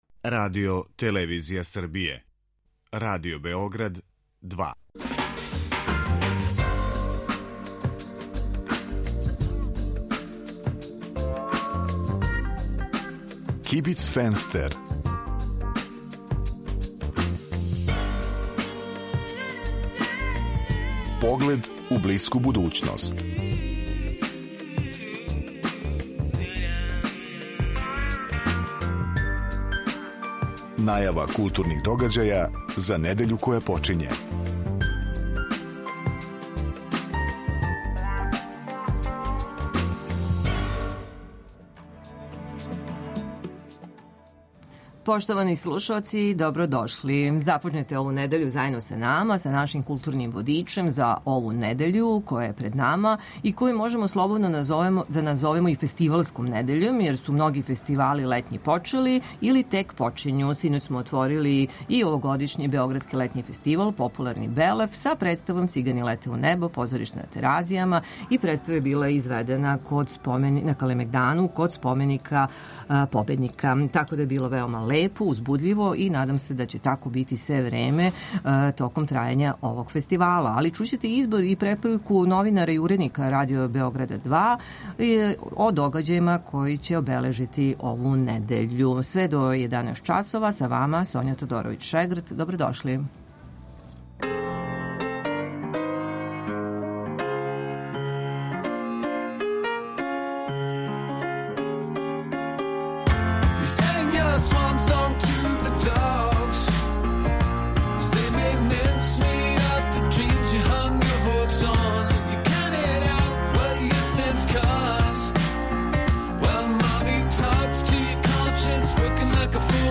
Осим тога чућете избор и препоруку новинара и уредника Радио Београда 2 из догађаја у култури који су у понуди у недељи пред нама.